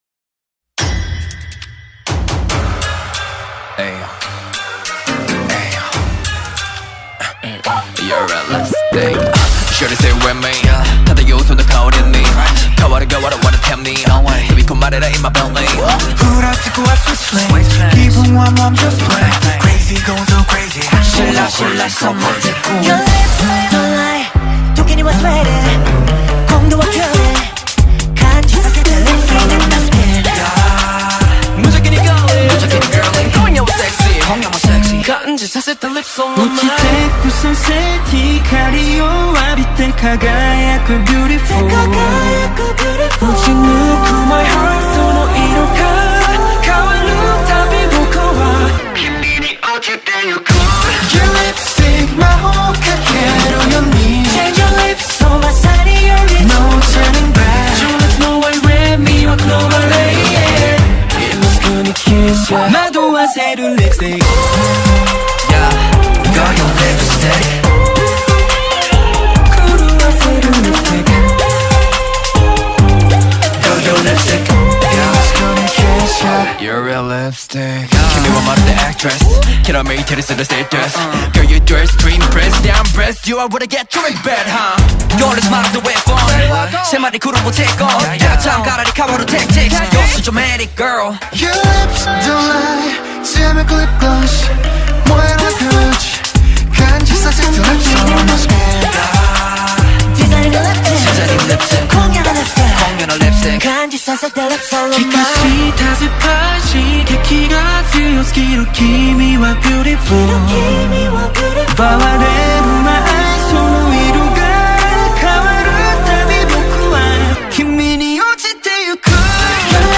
k-pop